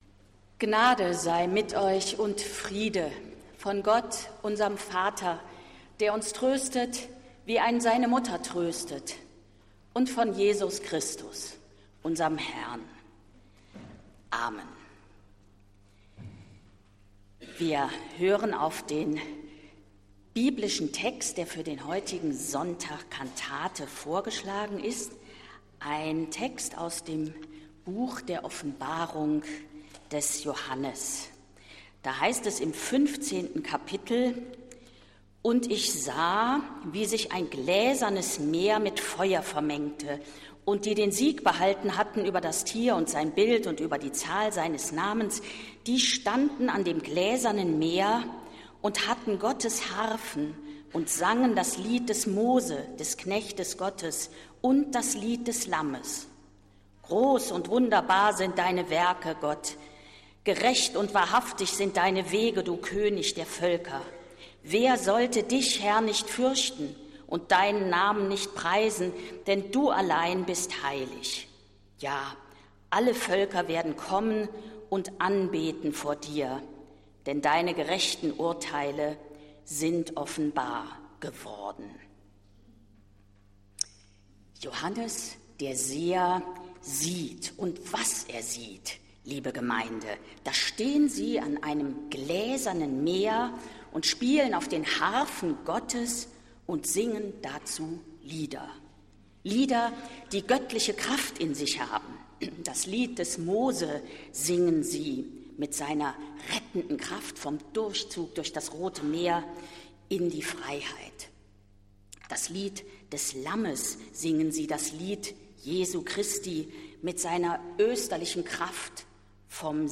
Predigt von Annette Kurschus anläßlich ihrer Ämtereinführung bei den v. Bodelschwinghschen Stiftungen Bethel
Am heutigen Sonntag wurde die Präses a. D., Dr. h. c. Annette Kurschus, in der Zionskirche in ihre Ämter bei den v. Bodelschwingschen Stiftungen Bethel eingeführt. Ihre Predigt können Sie noch einmal hören.